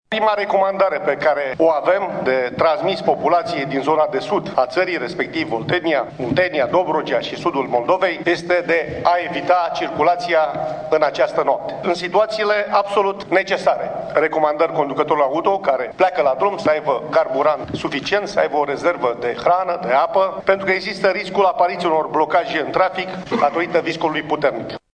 Vicepremierul Gabriel Oprea a atras astăzi atenţia, la Comitetul special pentru situaţii de urgenţă, întrunit la Ministerul de Interne, că din cauza viscolului, pot apărea blocaje pe principalele artere din zonele atenţionate.